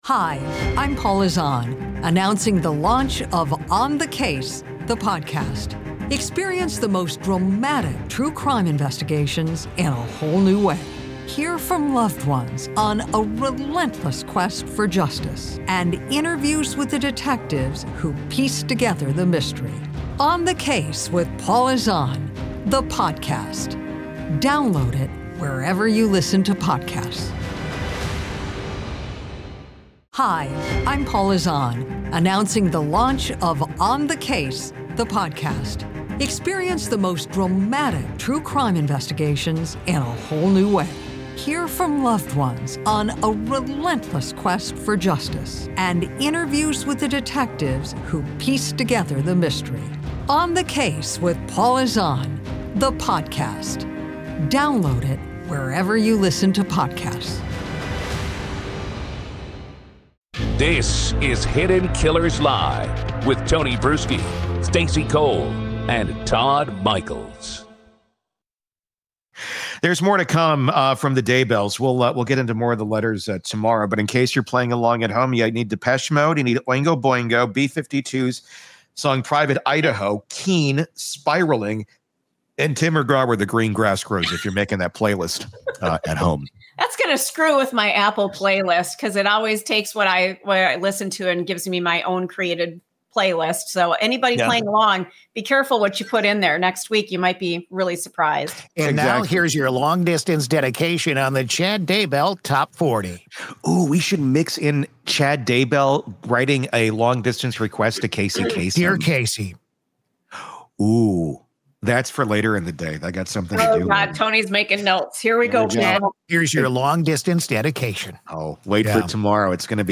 Hidden Killers Live closes out its Daybell coverage with a mix of dark humor and jaw-dropping audio. First, the hosts riff on Chad Daybell’s letters as if they were a “Top 40” radio countdown — imagining long-distance dedications from death row.
But the laughter fades when the show pivots to Lori Vallow’s Arizona courtroom monologue. In her own words, Lori rails against the justice system, claims she was denied a fair trial, and positions herself as a spiritual warrior for God.
The audio is surreal — a convicted killer preaching divine miracles while serving multiple life sentences.